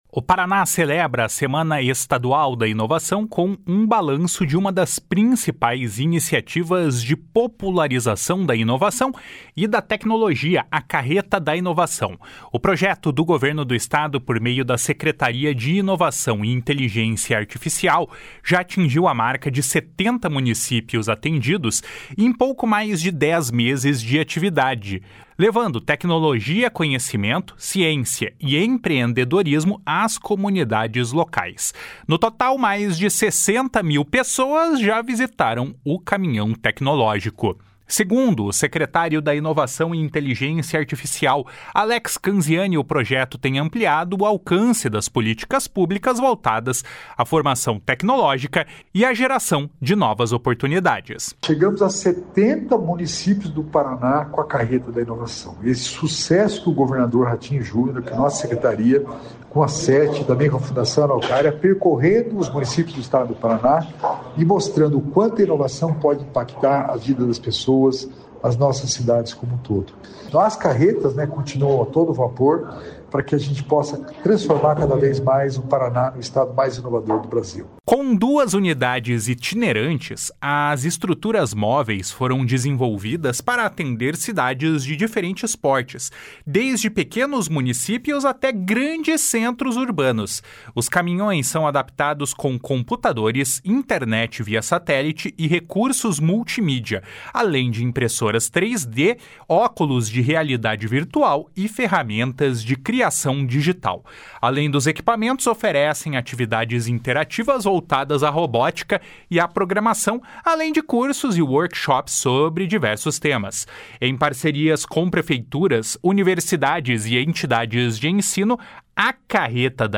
// SONORA ALEX CANZIANI //